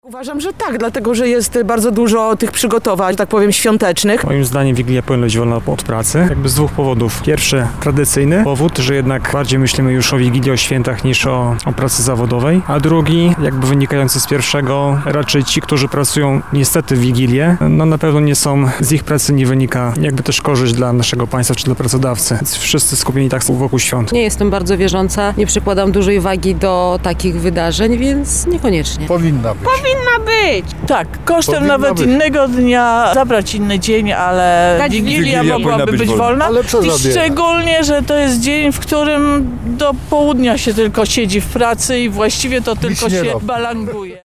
[SONDA] Co Lublinianie sądzą o wolnym w Wigilię?
O opinie w tej sprawie spytaliśmy mieszkańców Lublina.